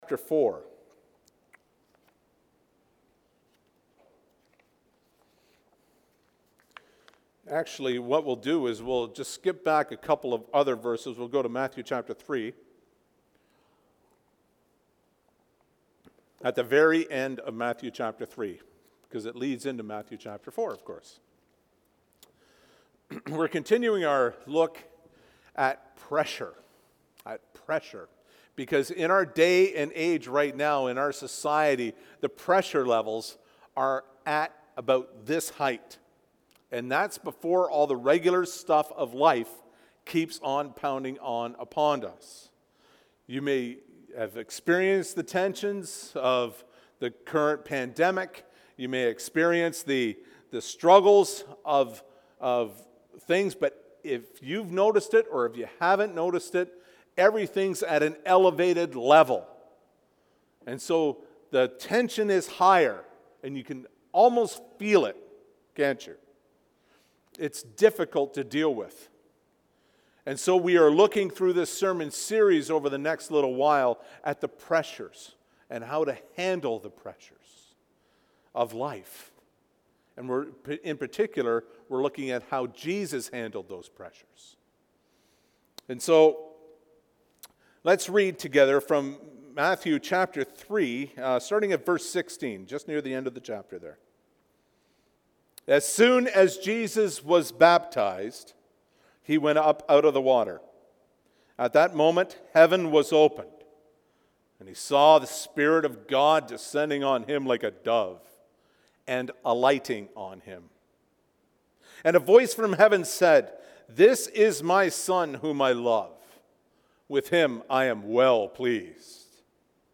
Sermon Messages